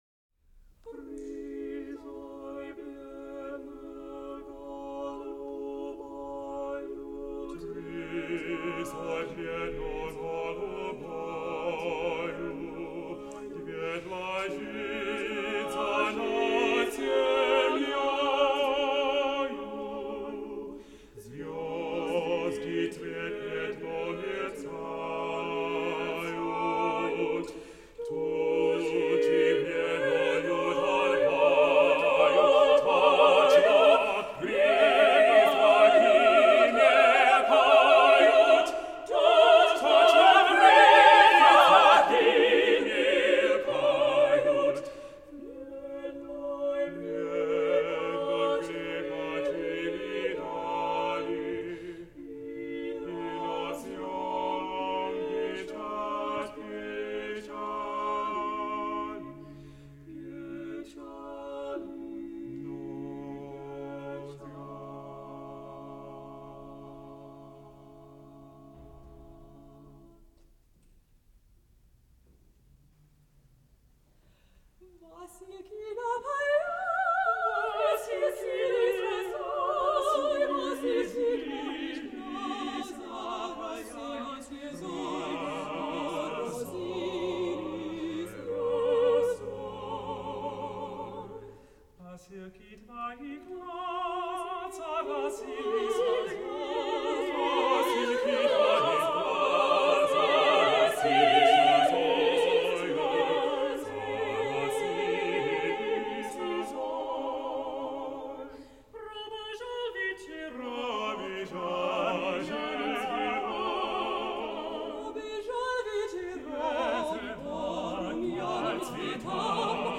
New York Art Vocal Ensemble, Vocal Quartet. César Antonovich Cui: Three vocal quartets, op. 59.